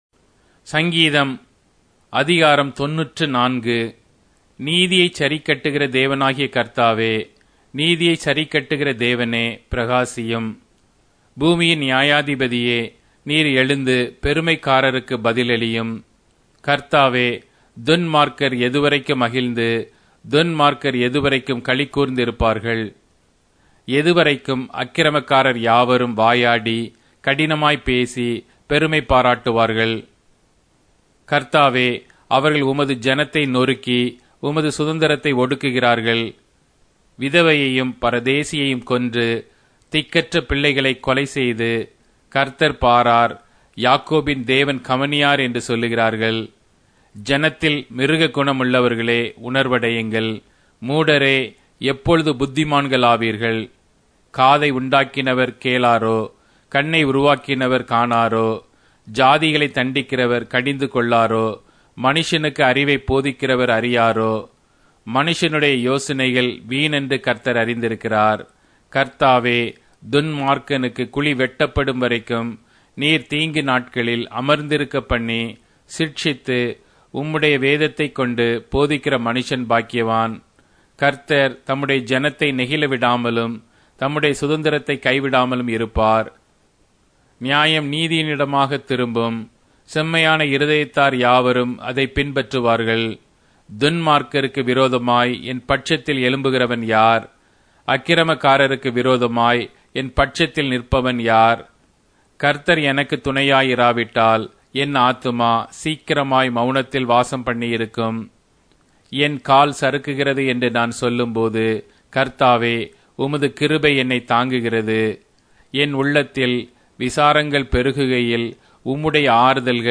Tamil Audio Bible - Psalms 99 in Nlt bible version